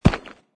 grassstone3.mp3